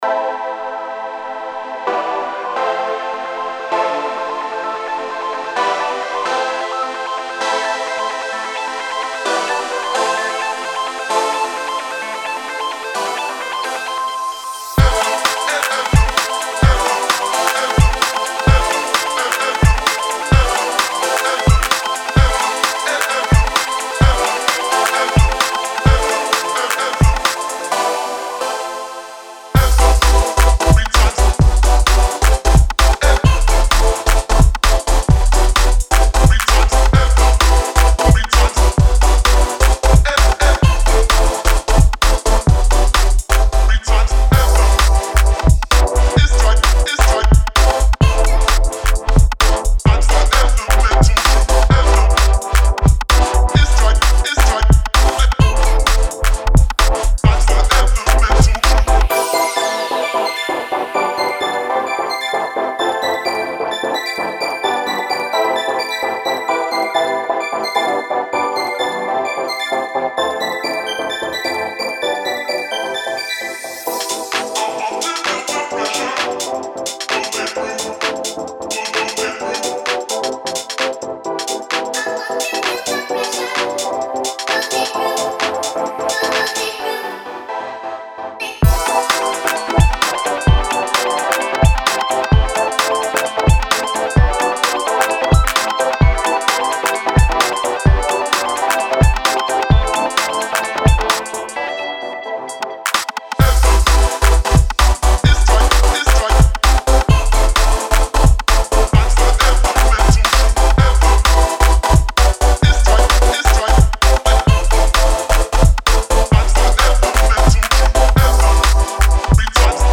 has some great feel good vibes